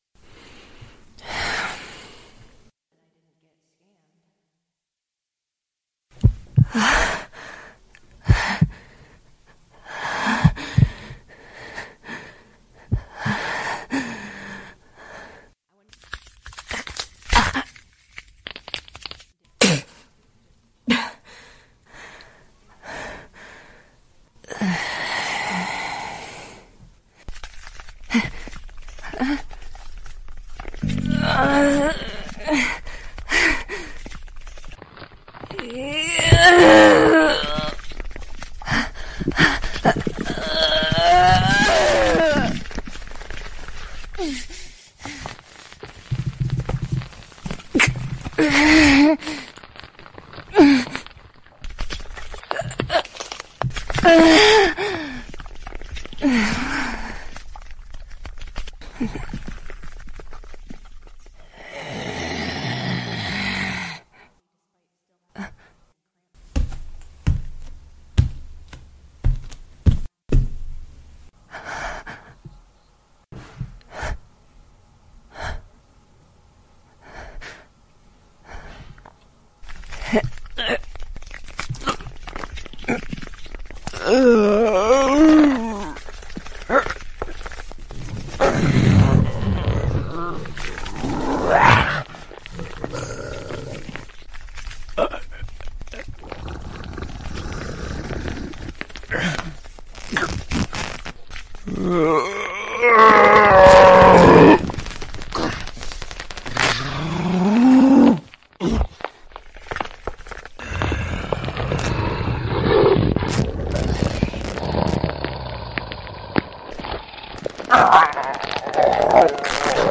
Lycanthrope Lunacy Audio - Just TF Sounds
Sound effects from Youtube, Pond5, and SoundDogs